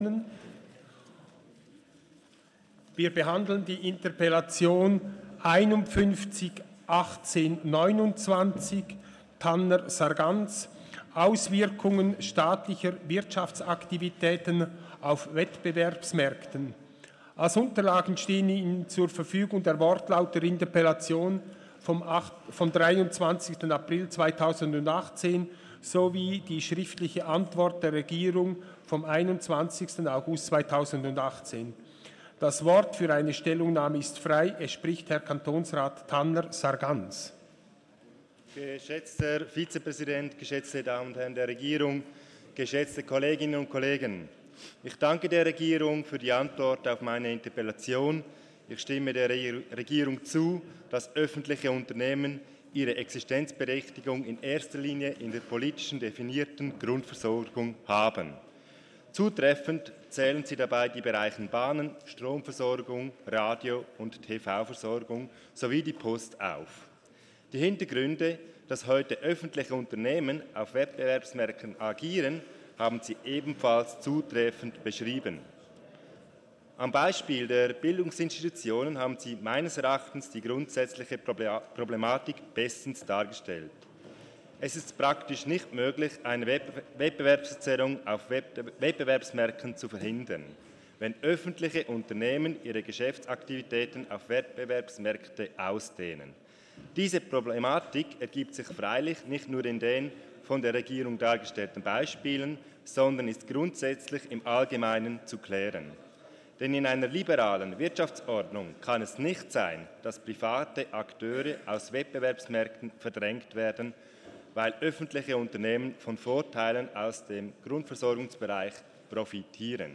18.2.2019Wortmeldung
Session des Kantonsrates vom 18. und 19. Februar 2019